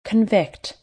Location: USA
Watch out! You may see this word with another pronunciation and its meaning is then different.